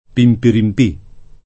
pimpirimp&+] o pimperimpera [pimperimp$ra] o pimperimpara [pimperimp#ra] s. m. — solo nella locuz. scherz. polvere del p. (o di p.), di sostanza a cui si attribuiscono poteri miracolosi